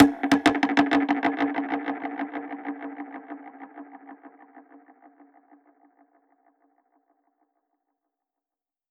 DPFX_PercHit_C_95-04.wav